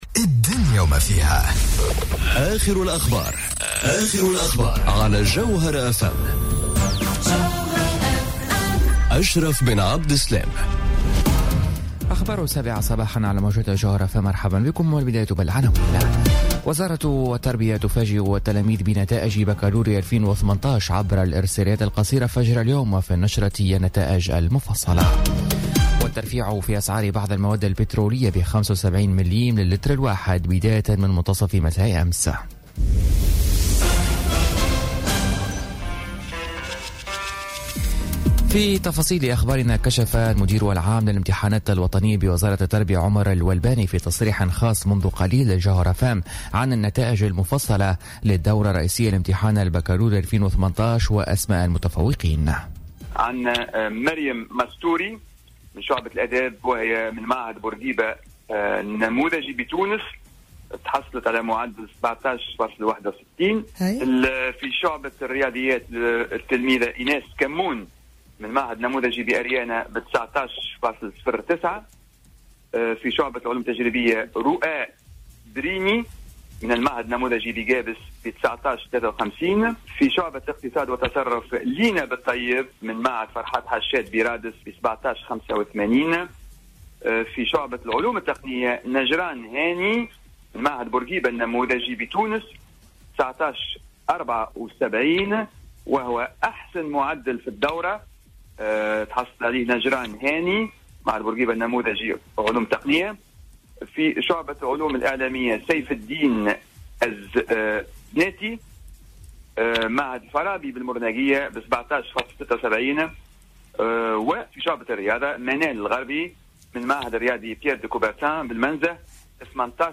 نشرة أخبار السابعة صباحا ليوم السبت 23 جوان 2018